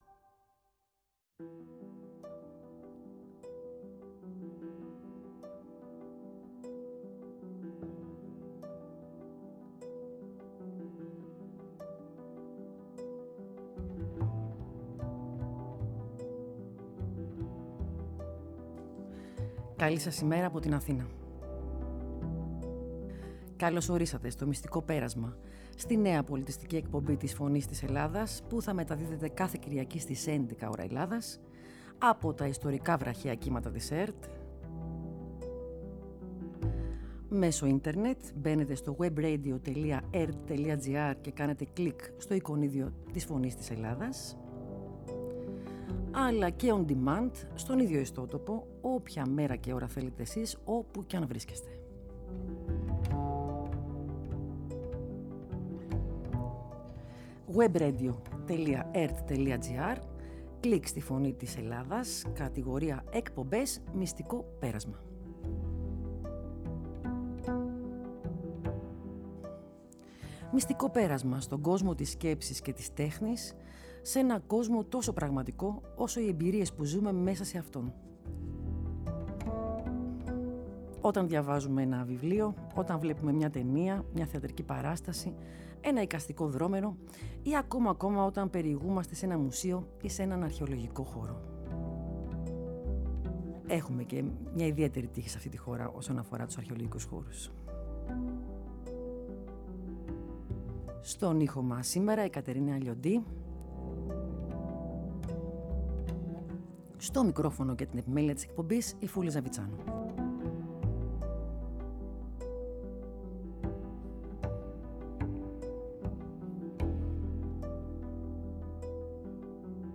πολιτιστική εκπομπή “Μυστικό πέρασμα